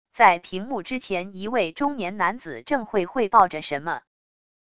在屏幕之前一位中年男子正会汇报着什么wav音频